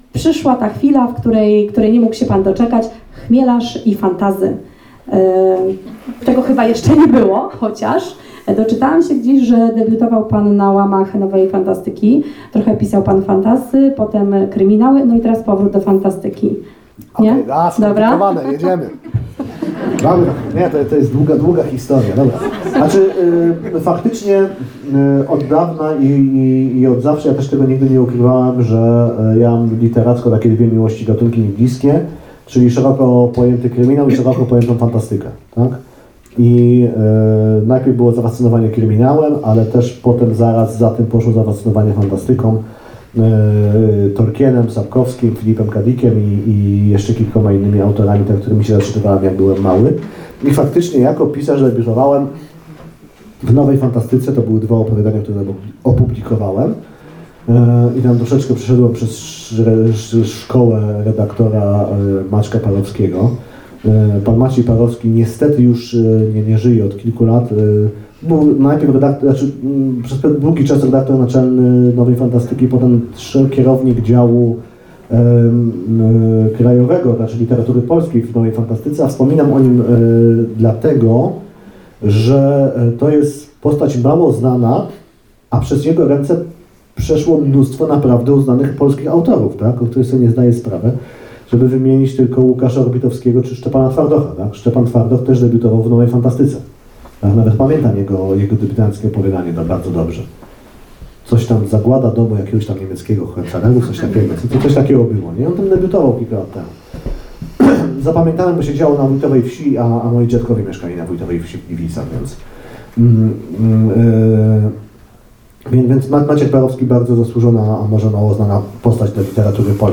„Żmijowkisko”, „Farma lalek” czy „Dług honorowy” – to tylko niektóre powieści napisane przez Wojciecha Chmielarza. Autor niedawno był w Radomiu na spotkaniu autorskim w bibliotece.